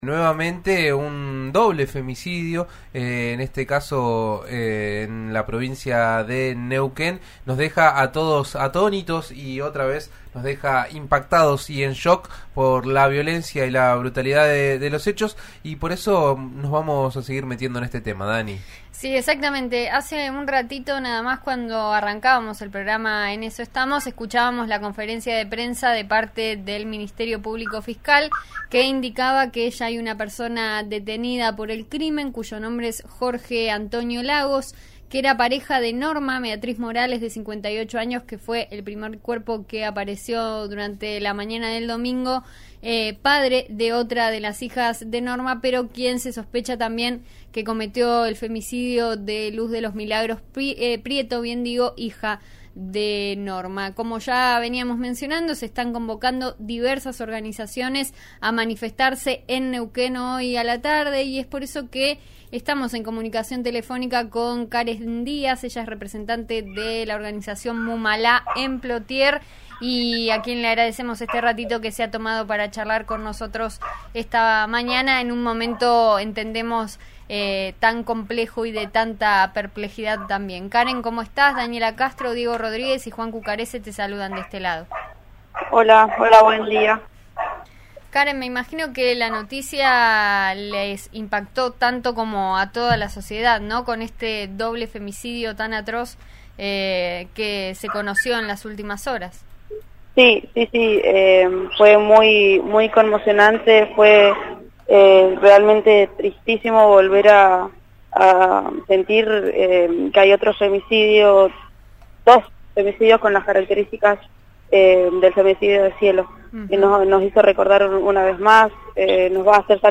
Miles de personas exigieron justicia por los dos atroces femicidios que se conocieron el domingo y para reclamar políticas públicas contra la violencia de género. La marcha fue hasta la ex ruta 22, volvió al centro neuquino y culminó con un acto en Casa de Gobierno. Una columna de cinco cuadras recorrió las calles de la capital provincial.